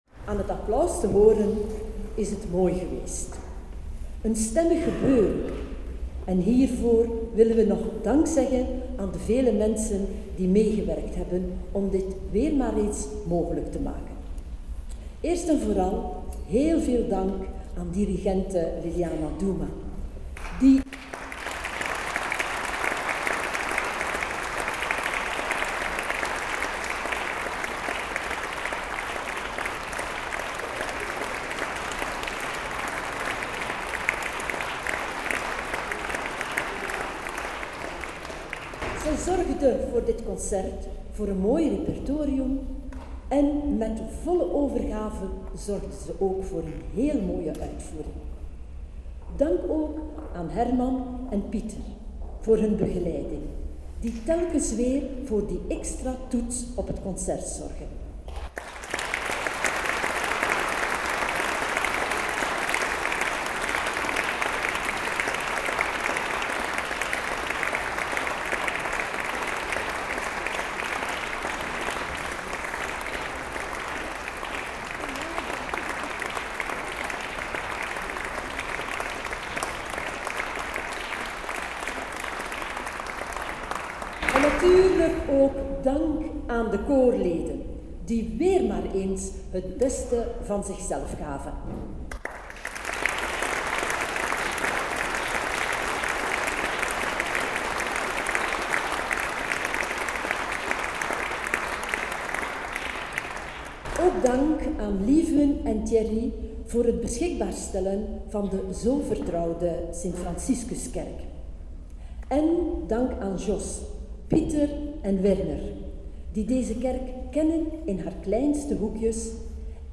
Kerstconcert Dreaming of a white Christmas
Kerk St.-Franciscus Heverlee